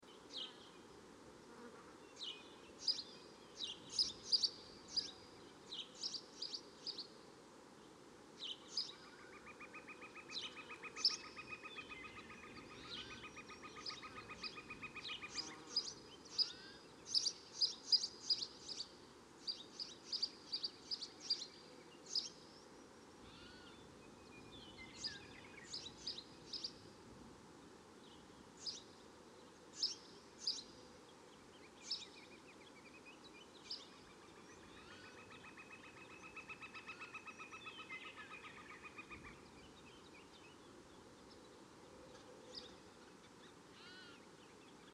دانلود آهنگ پرنده 28 از افکت صوتی انسان و موجودات زنده
جلوه های صوتی
دانلود صدای پرنده 28 از ساعد نیوز با لینک مستقیم و کیفیت بالا